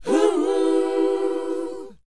WHOA D D.wav